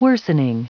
Prononciation du mot worsening en anglais (fichier audio)
Prononciation du mot : worsening